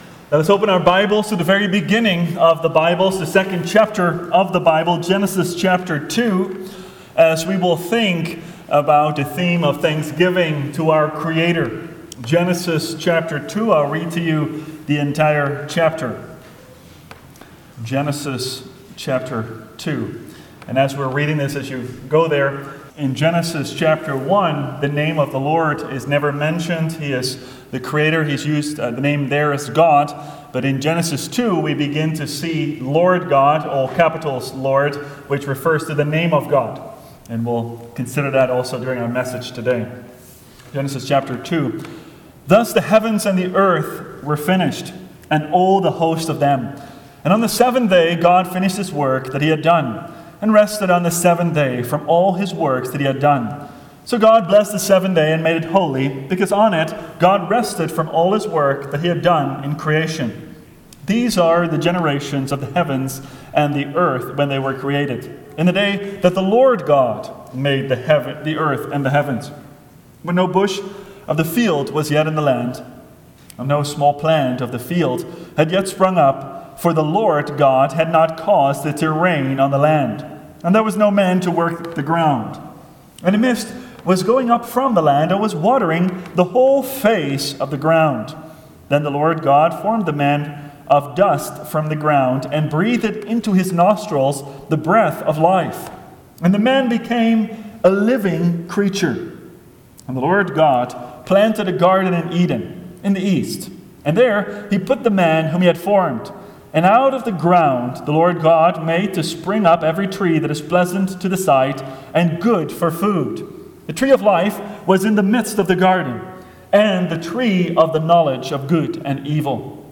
Thanksgiving Sermons